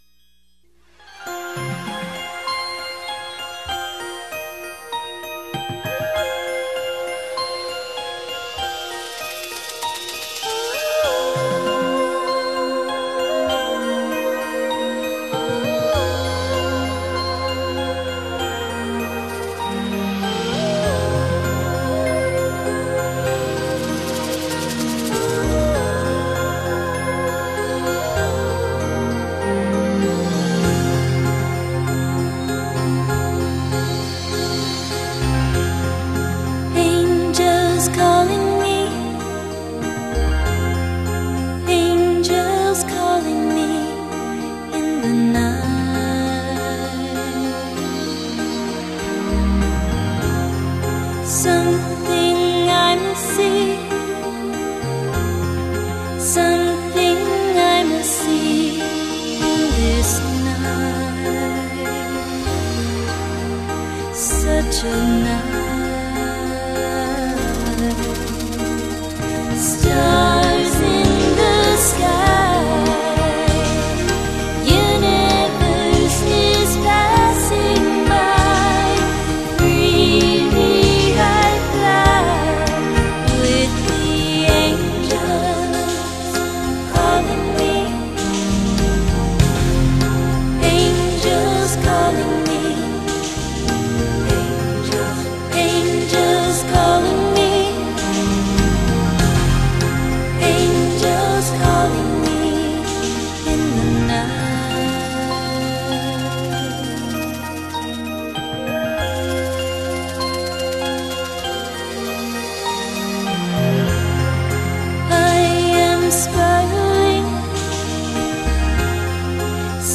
整张CD中的音乐，绝大多数都以具有天籁音色的甜美人声来伴随和引导电子化的旋律行进。
此碟的音响，虽带有明显的装饰味，但丝毫不会影响到音乐中人性化的内涵，评之为录音室中的一流制作是无可非议的。
一尘 不染的旋律，轻柔曼妙的伴奏，大自然生动的音响，还有 精致无比的震撼录音